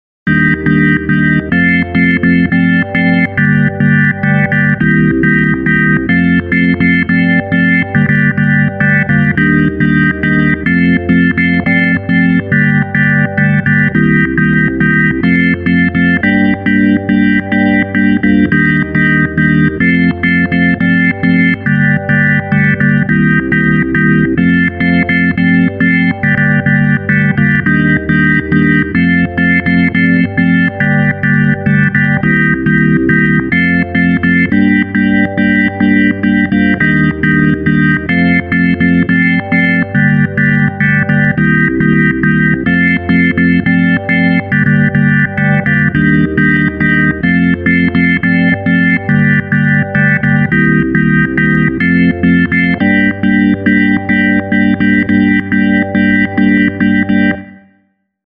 Block Chords 01